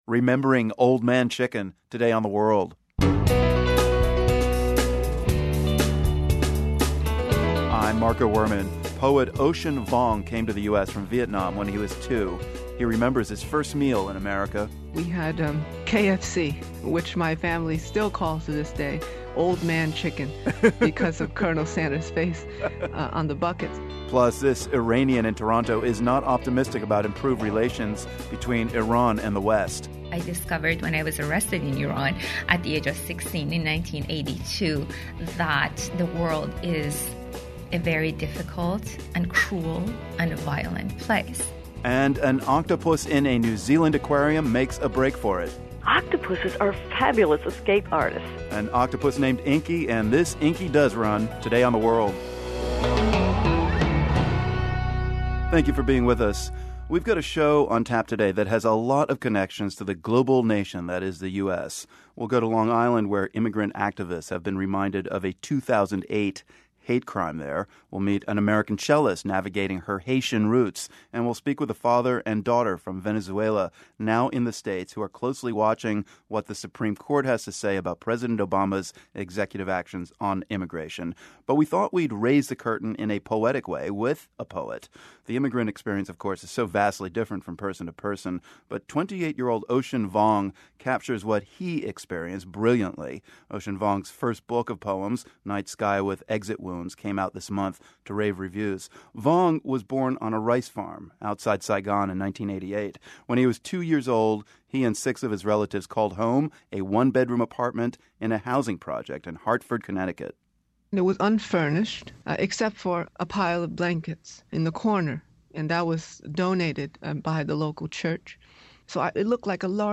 A daughter and her father speak about a controversial immigration debate that could affect millions of people in the US. Next week, the Supreme Court will hear oral arguments about the legality of DACA, President Barack Obama's executive action on immigration.
We also hear from two Americans with immigrant backgrounds who are lighting up the world of poetry and literature.